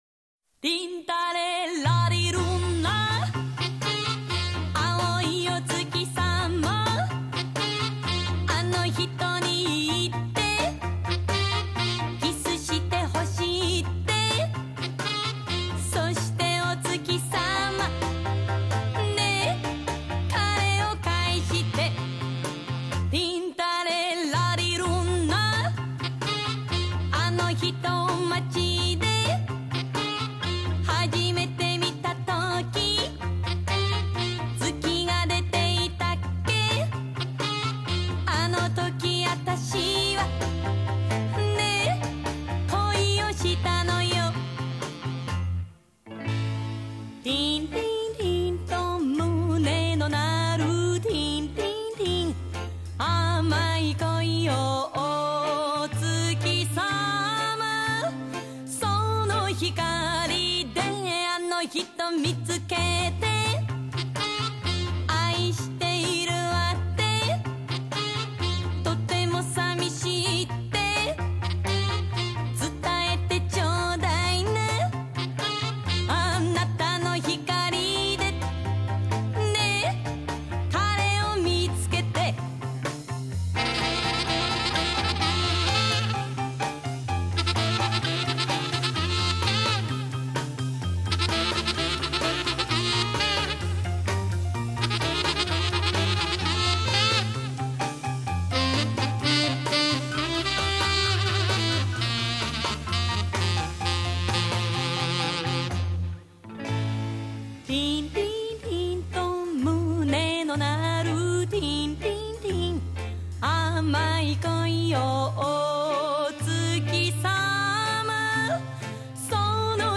大好きなイタリアン・ポップへ。
声のパワー、声域の広さ、突っ込みの鋭さ、ノリの良さ。
音は少し高いほうを持ち上げてある。
とか言ってたら間奏つき（踊りじゃなかった）のを見つけたので